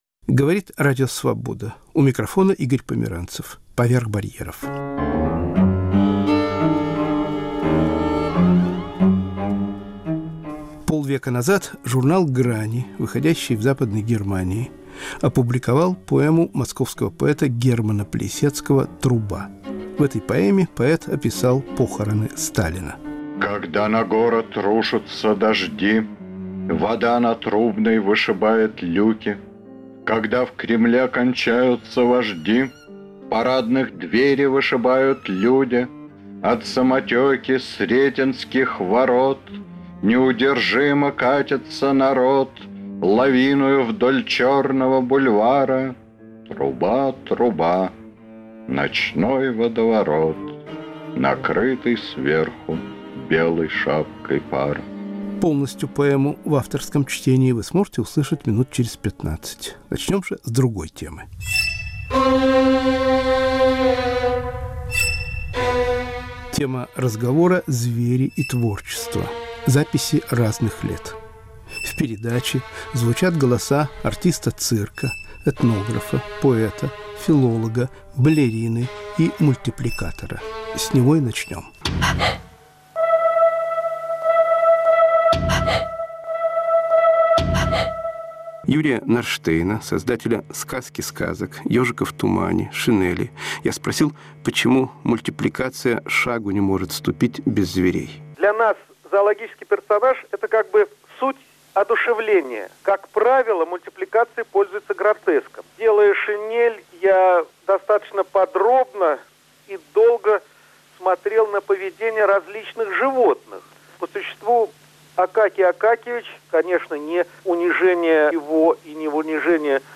В передаче звучат голоса Ю .Никулина, Ю. Норштейна, А. Парщикова и др.** Поэма «Труба» Германа Плисецкого.